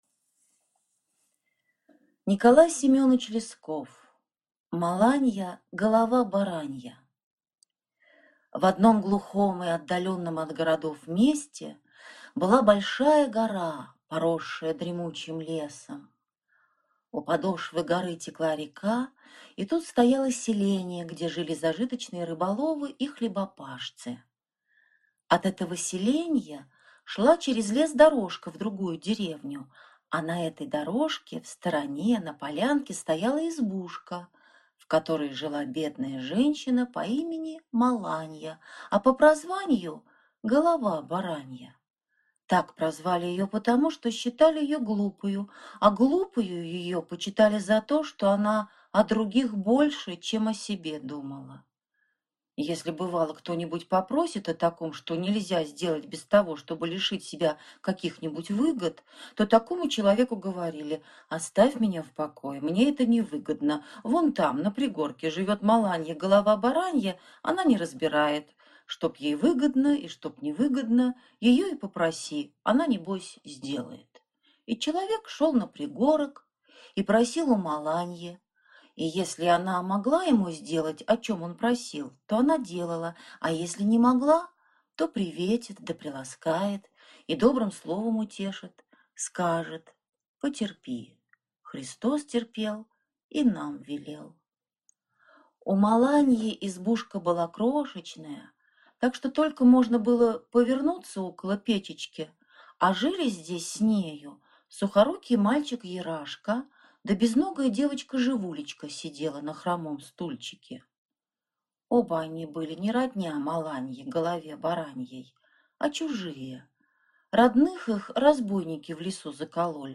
Аудиокнига Маланья – голова баранья | Библиотека аудиокниг